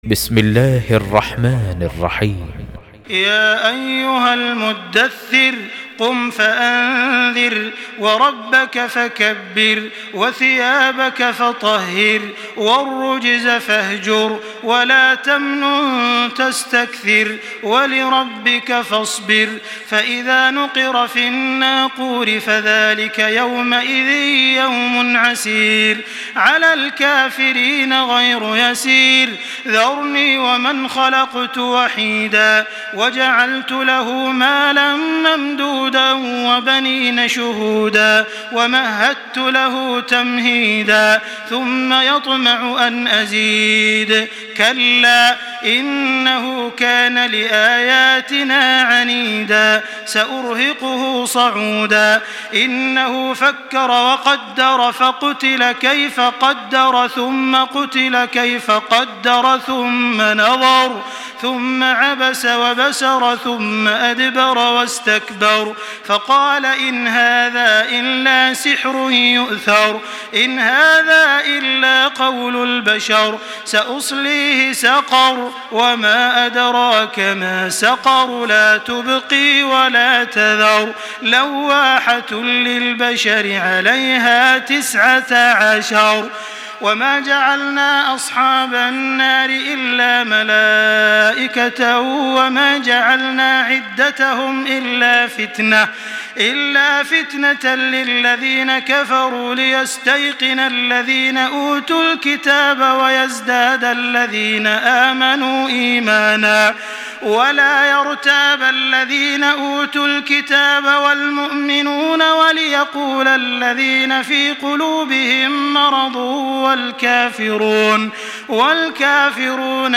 Sourate Al Muddaththir du cheikh tarawih makkah 1425 en mp3, lire et telecharger sourate Al Muddaththir